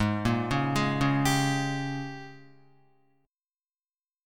G#M7sus2sus4 chord